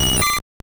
Cri de Chenipan dans Pokémon Or et Argent.